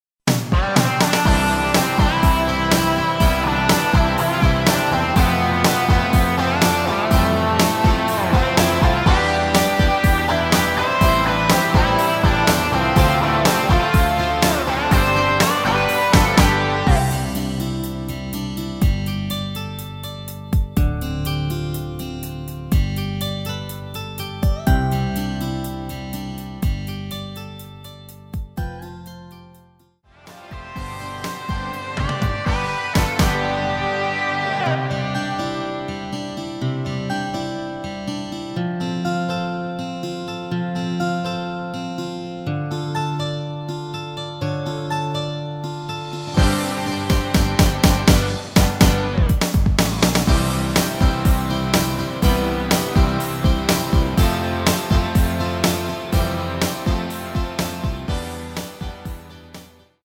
원키에서(-5)내린 MR입니다.
앞부분30초, 뒷부분30초씩 편집해서 올려 드리고 있습니다.
중간에 음이 끈어지고 다시 나오는 이유는